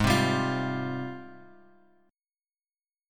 G#M13 chord